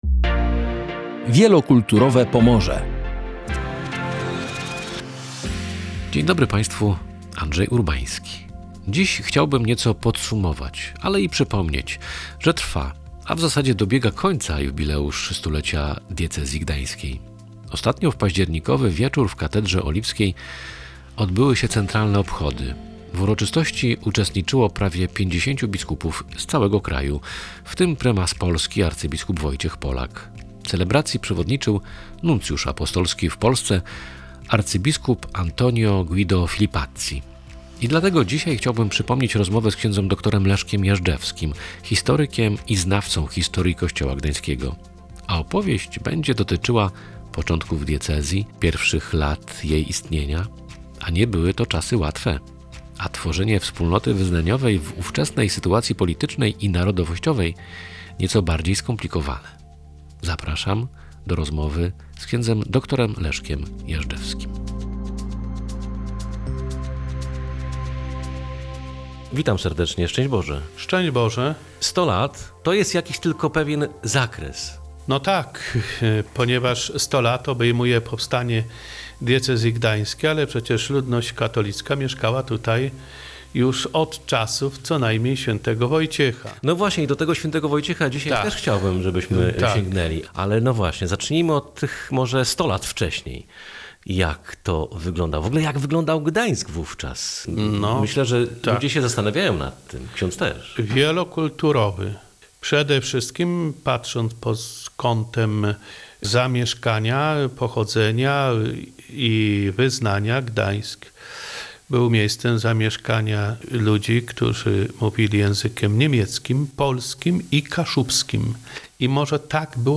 Z tego względu przypominamy rozmowę